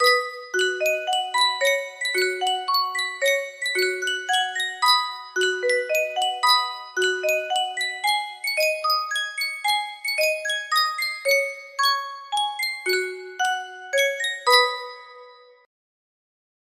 Sankyo Music Box - Schubert Symphony No. 8 U2 music box melody
Full range 60